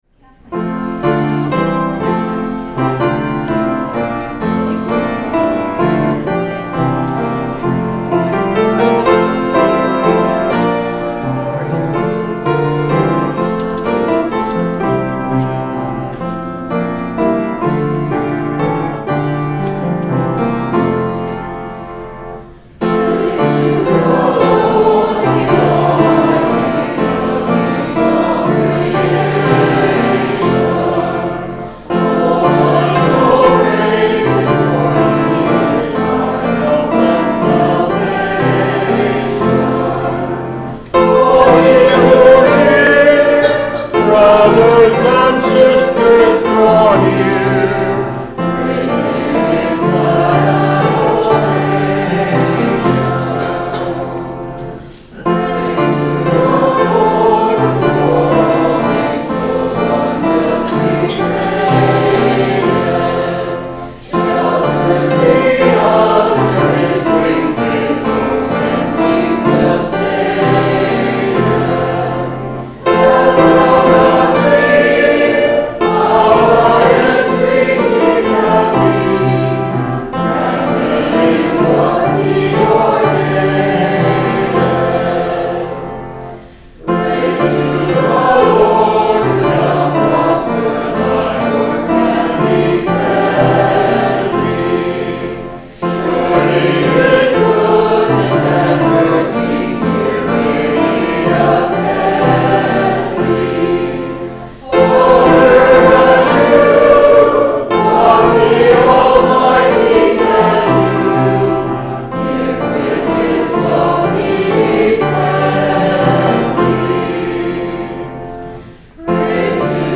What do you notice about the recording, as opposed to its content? FOURTEENTH SUNDAY AFTER PENTECOST